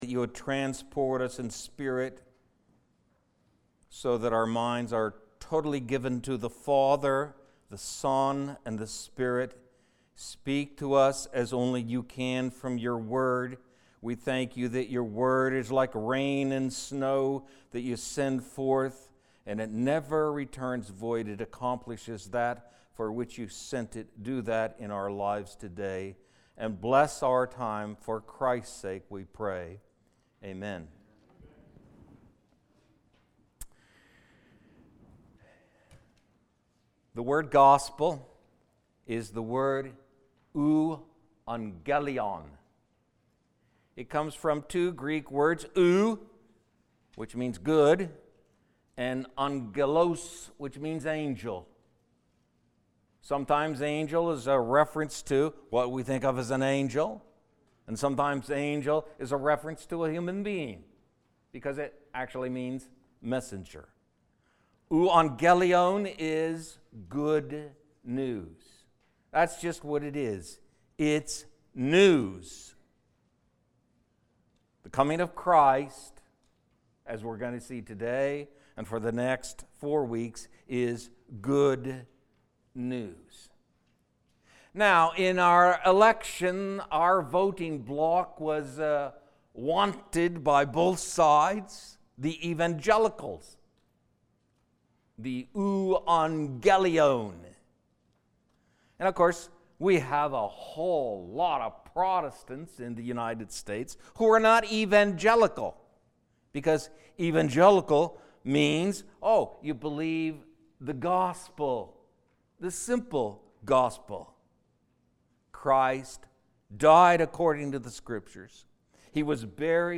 A message from the series "Seasonal Sermons."